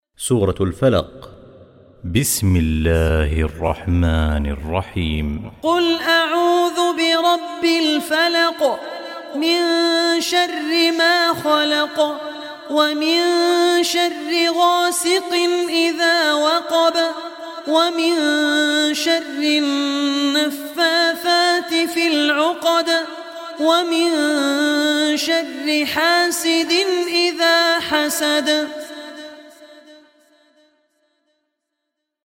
Surah Falaq Recitation by Abdur Rahman Al Ossi
Surah Falaq, listen online mp3 recitation / tilawat in the voice of Sheikh Abdur Rahman Al Ossi.